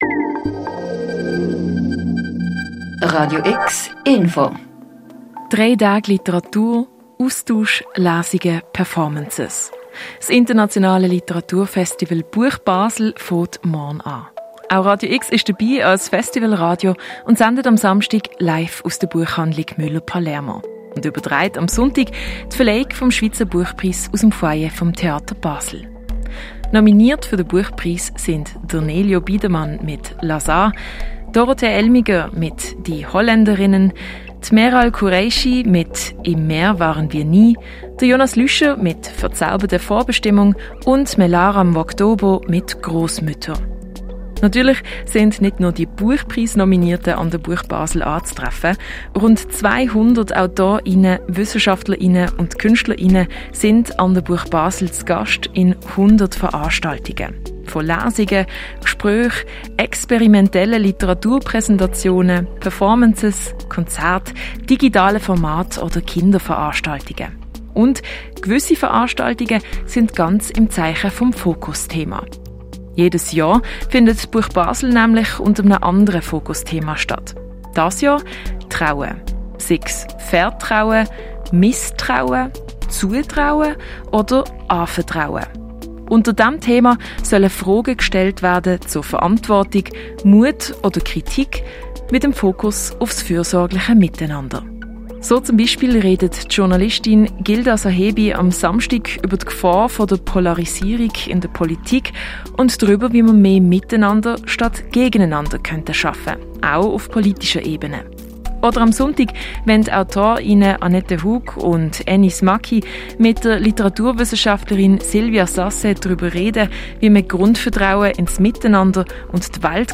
Radio X Live an der BuchBasel 2025